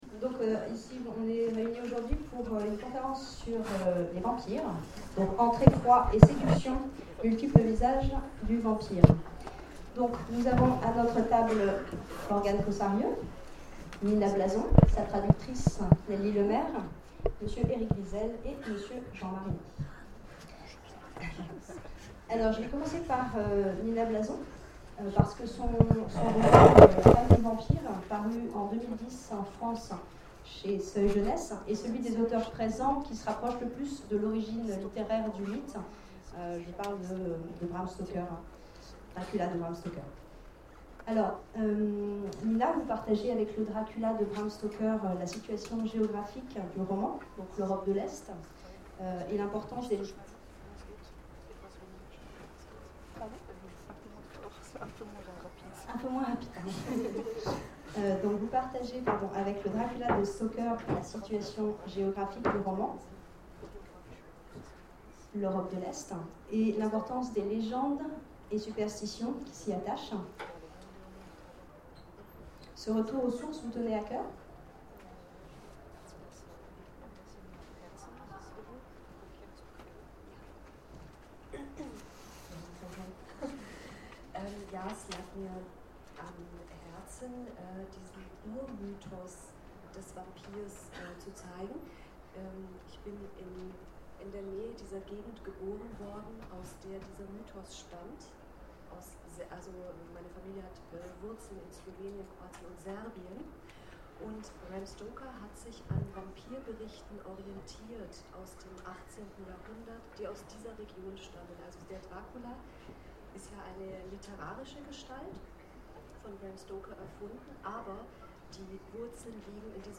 Imaginales 2013 : Conférence Entre effroi et séduction...